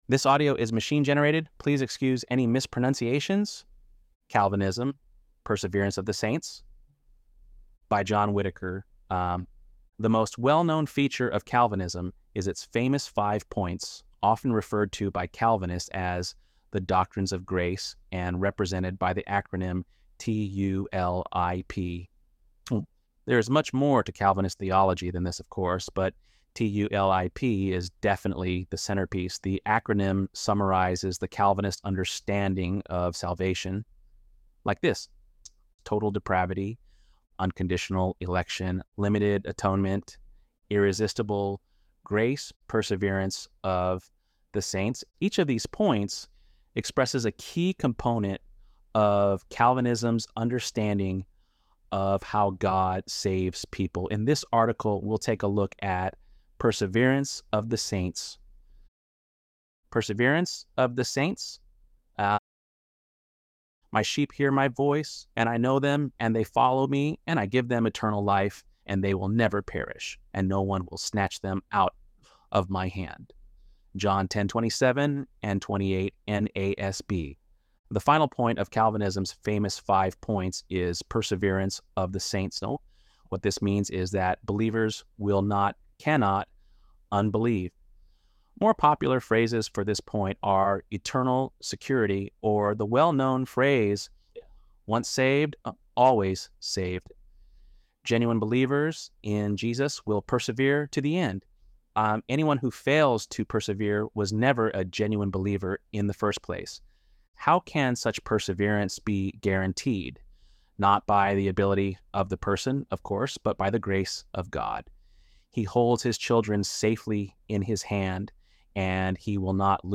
ElevenLabs_8.7_Calvinism.mp3